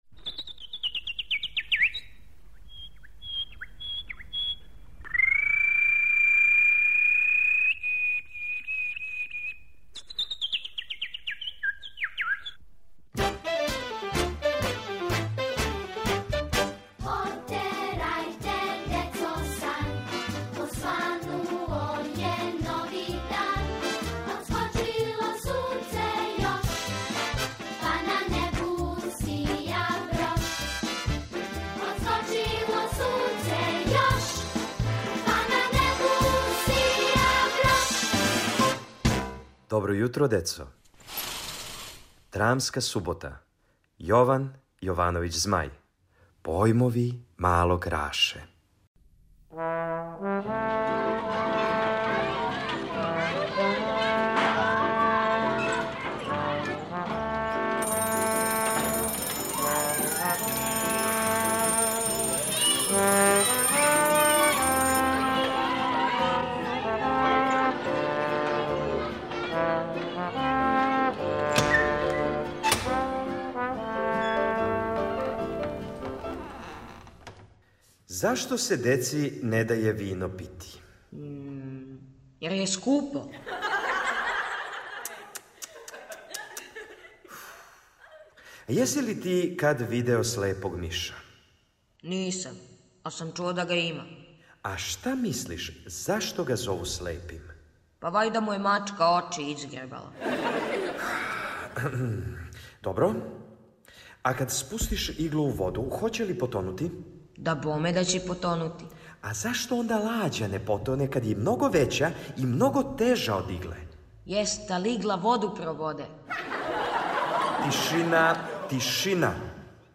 Слушате кратке драме по текстовима Јована Јовановића Змаја "Појмови малог Раше" и "Добри суседи".